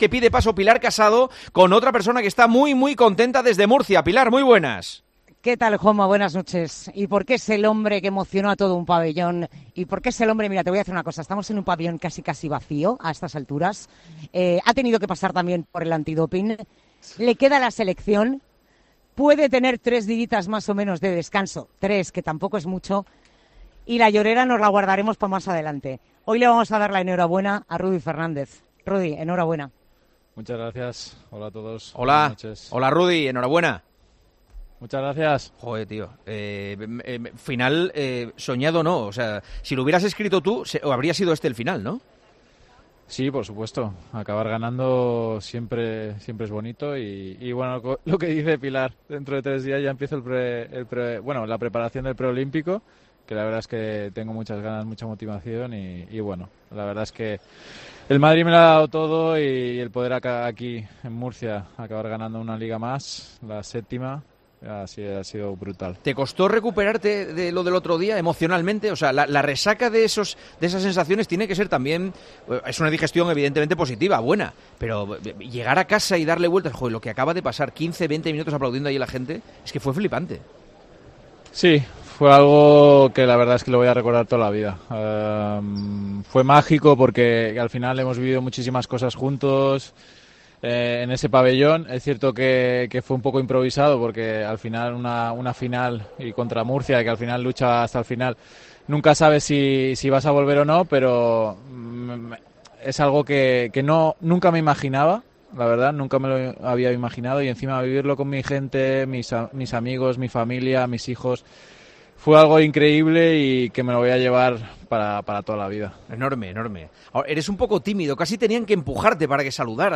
Rudy Fernández se ofrece entre risas a ser tertuliano de El Partidazo de COPE: "Cuando queráis"
Rudy Fernández ha pasado este miércoles por los micrófonos de El Partidazo de COPE tras conquistar la Liga ACB con el Real Madrid.